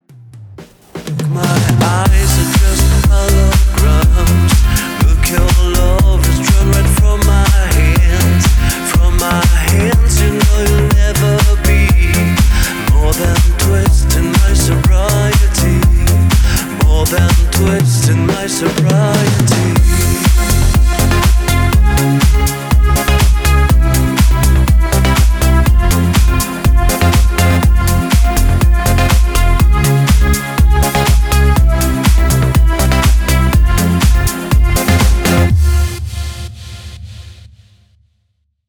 • Качество: 320, Stereo
deep house
dance
Стиль: House, Deep House